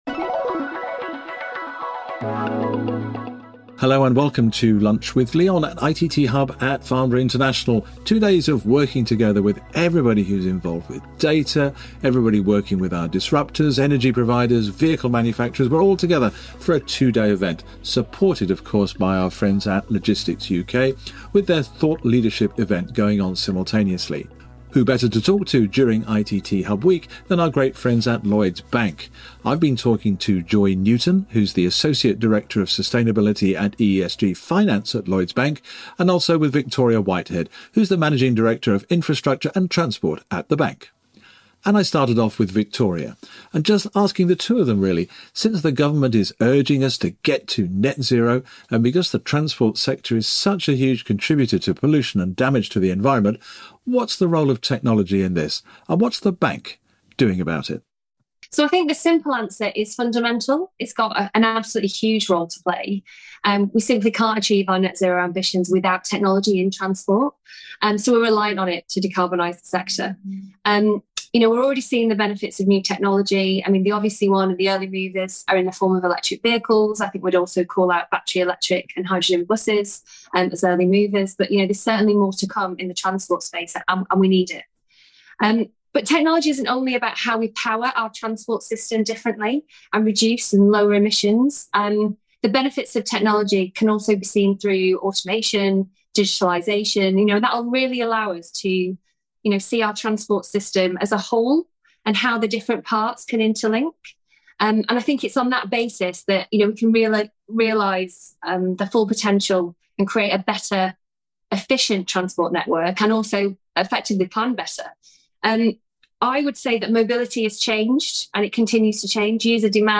The conversation ranges from the ‘Energy Trilemma’ – finding a balance between security, affordability, and sustainability – to how we are seeing the same thing in the transport sector.